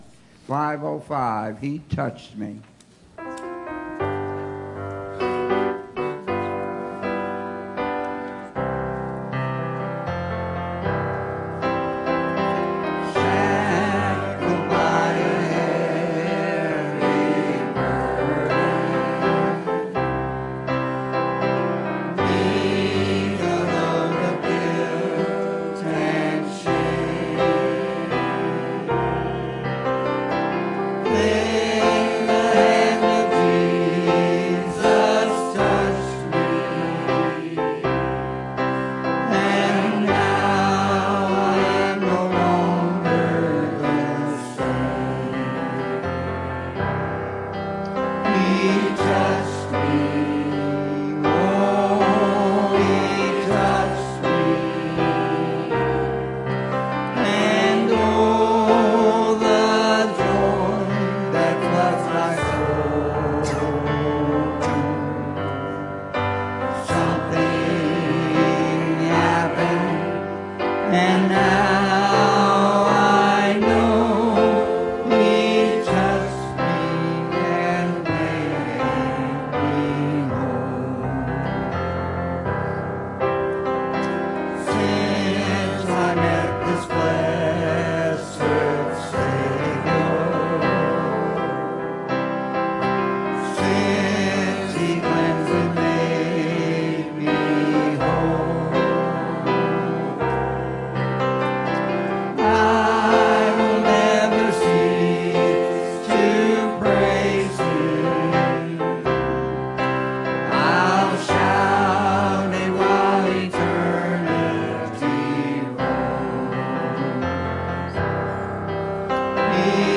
Bible Text: Revelation 6:12-17 | Preacher